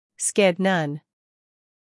英音/ skɪd / 美音/ skɪd /